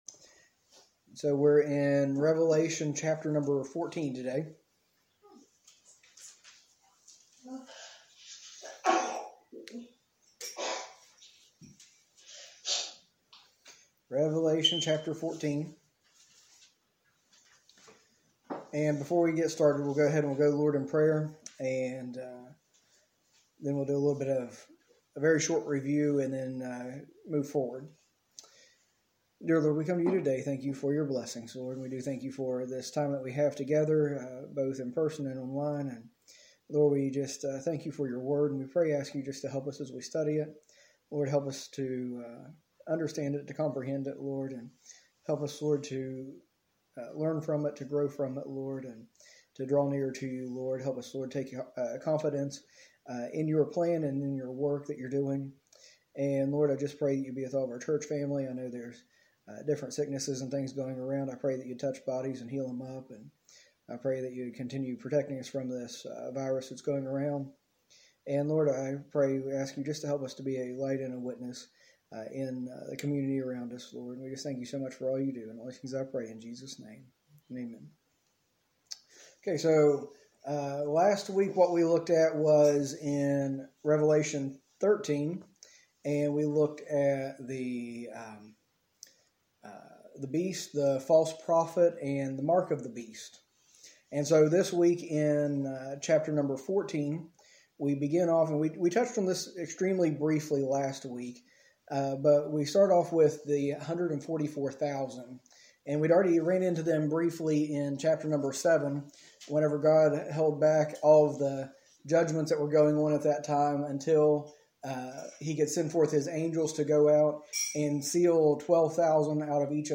A message from the series "Study on the End Times."